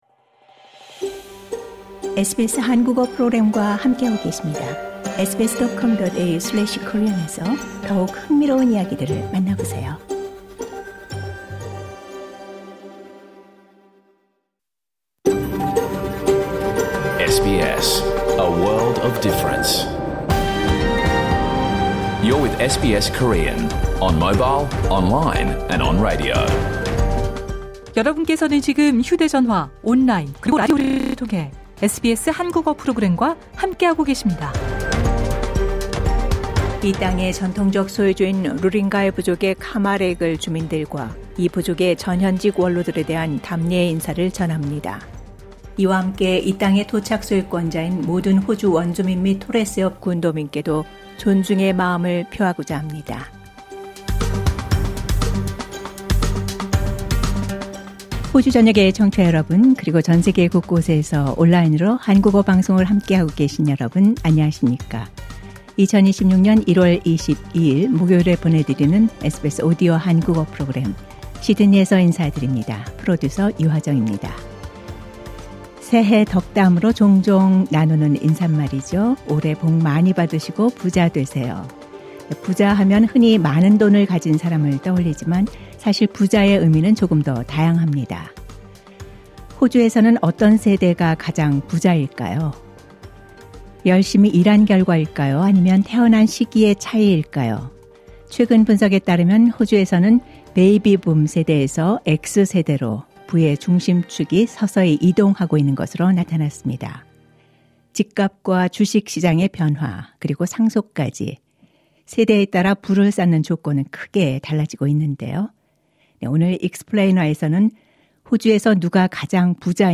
2026년 1월 22일 목요일에 방송된 SBS 한국어 프로그램 전체를 들으실 수 있습니다.